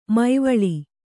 ♪ maivaḷi